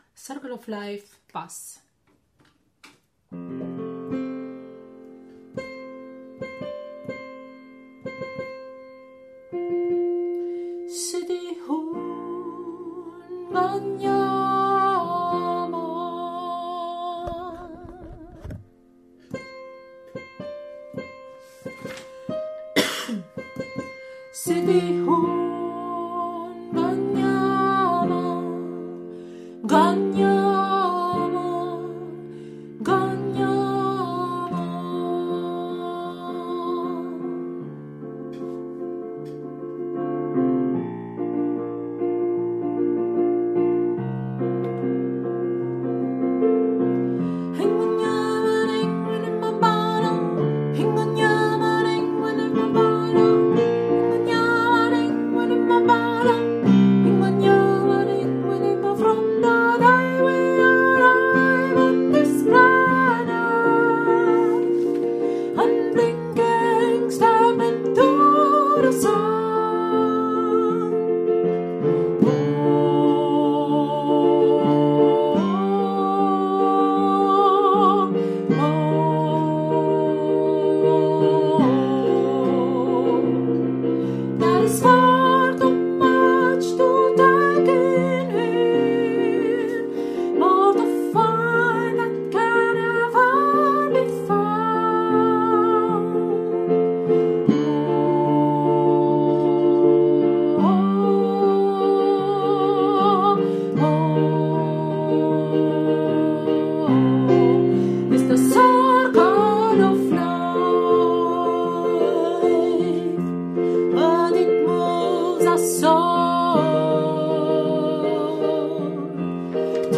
Circle of life – Bass (cut version)